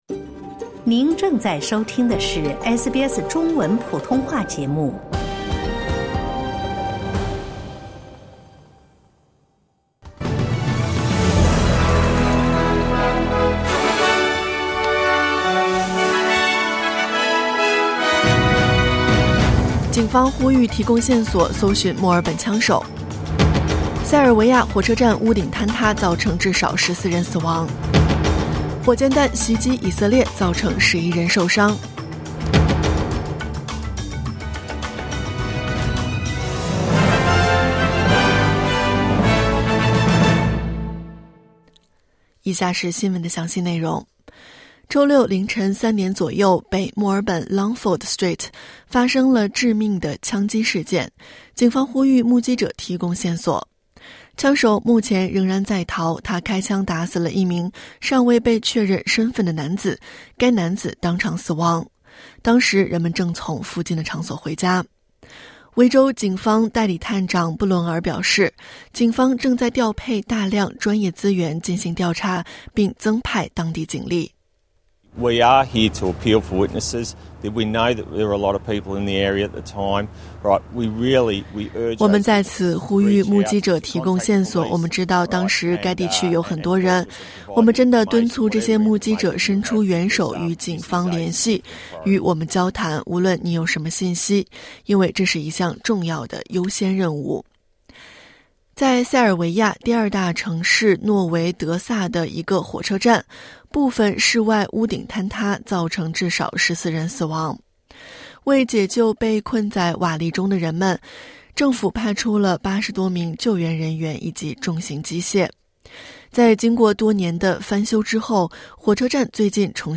SBS早新闻（2024年11月3日）
SBS Mandarin morning news Source: Getty / Getty Images